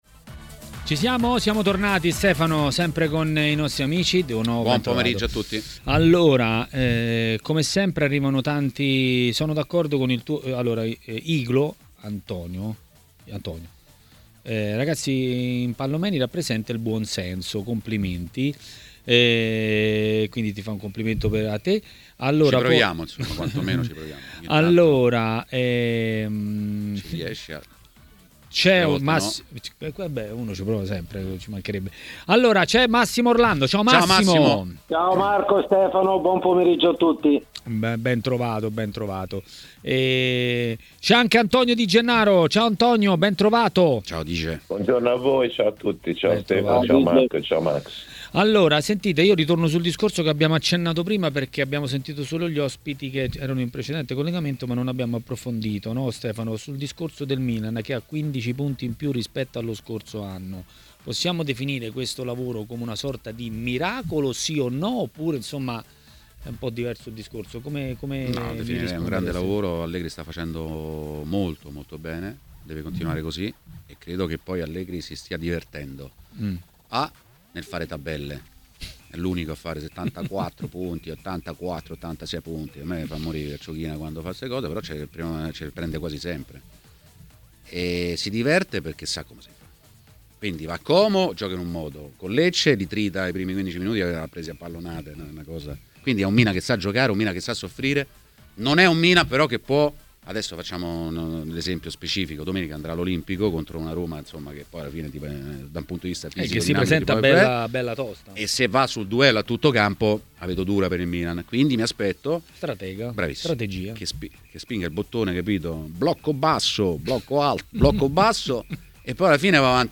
A Maracanà, nel pomeriggio di TMW Radio, è intervenuto l'ex calciatore e commentatore tv Antonio Di Gennaro.